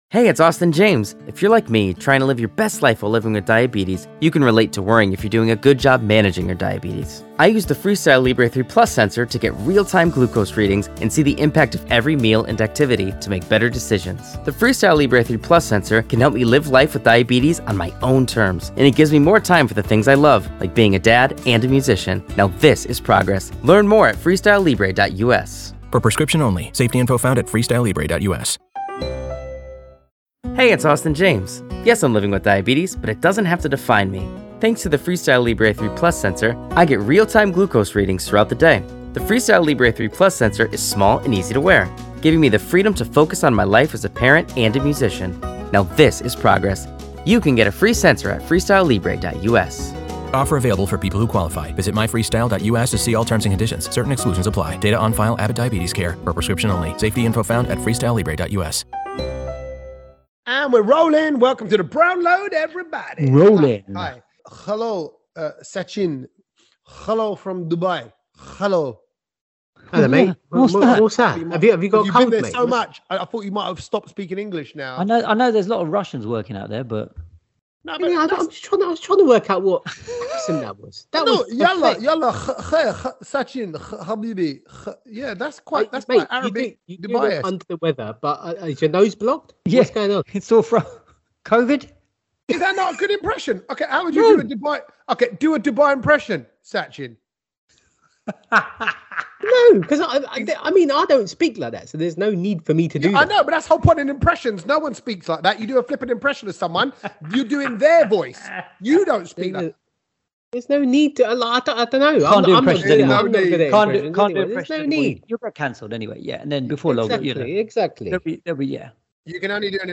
so we’re all online for this one!